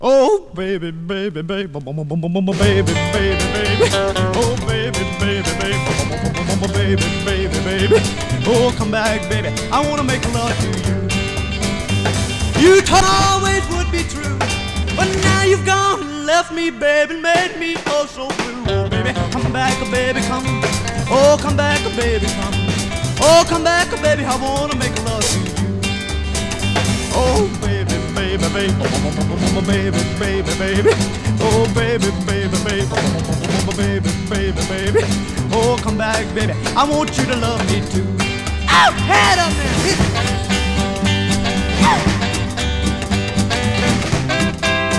Rock, Rockabilly　Italy　12inchレコード　33rpm　Mono